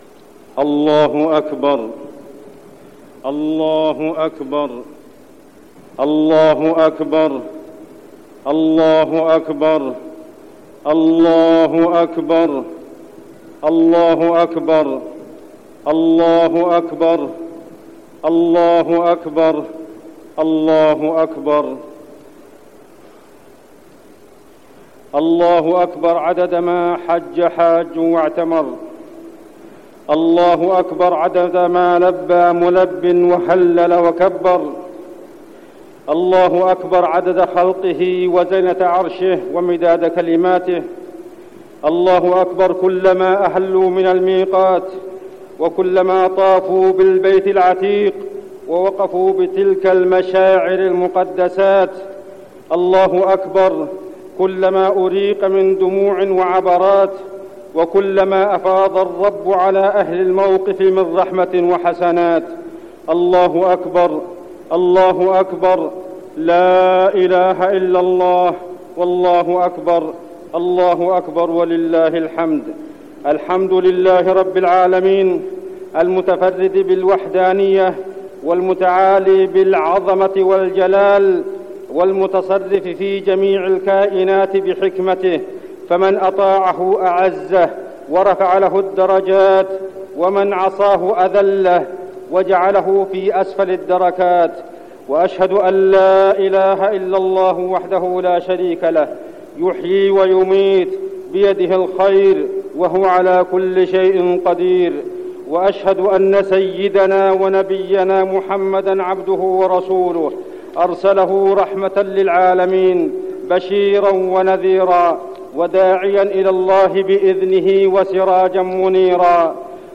خطبة عيد الأضحى
تاريخ النشر ١٠ ذو الحجة ١٤٠٧ هـ المكان: المسجد النبوي الشيخ